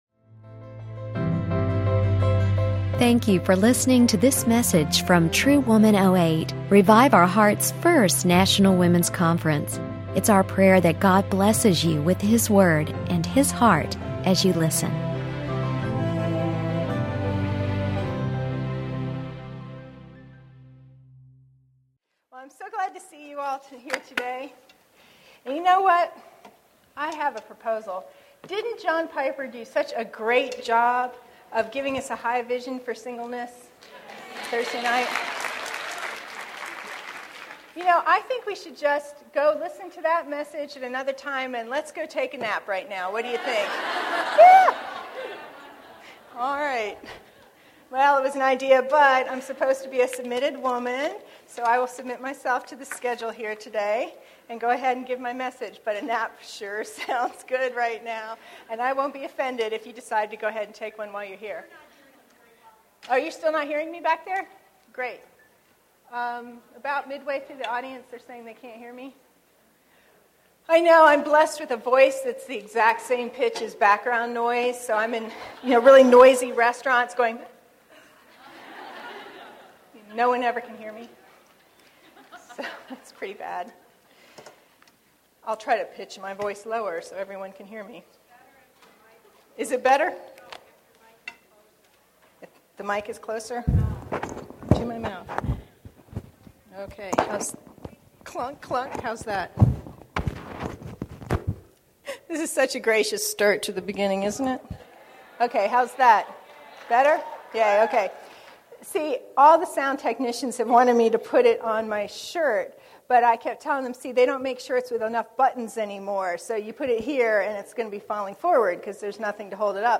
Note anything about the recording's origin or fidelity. Look at "solo femininity" through the lens of Proverbs 31. This session provides a road map for the single season and much encouragement for the journey.